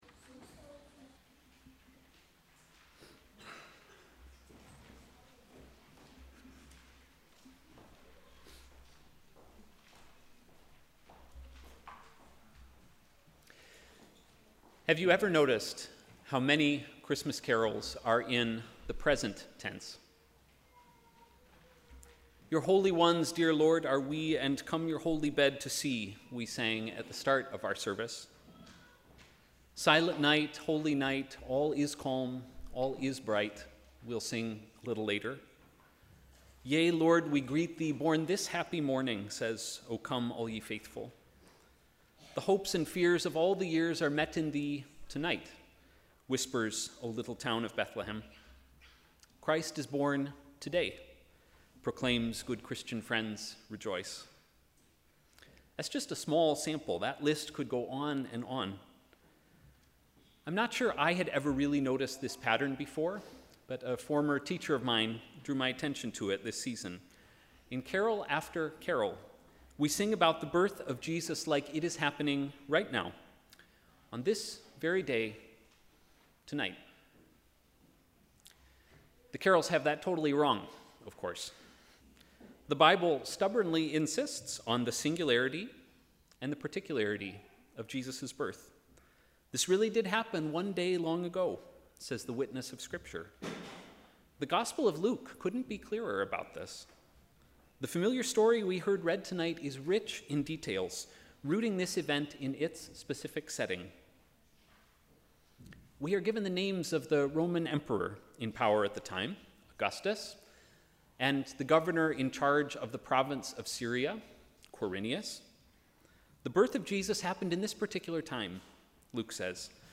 Sermon: ‘How will we respond?’
Sermon_ChristmasEve.mp3